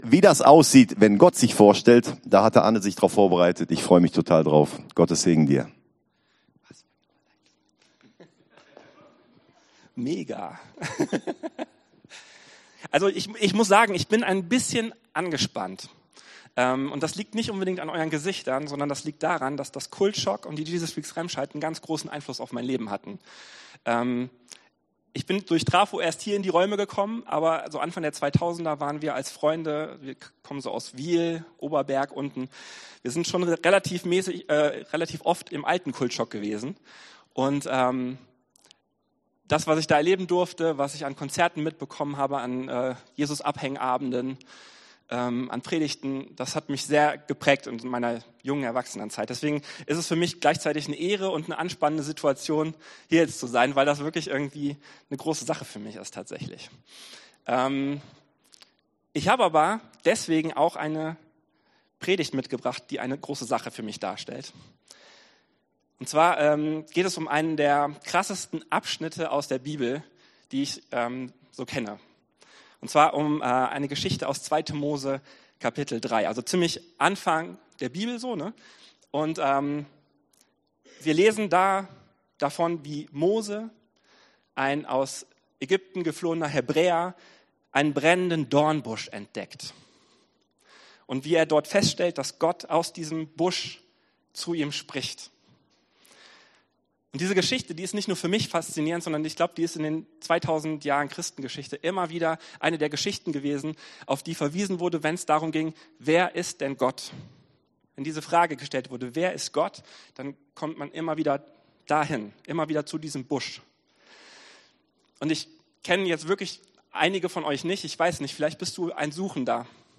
Auf dieser Seite findest du unsere Predigten der letzten 18 Jahre.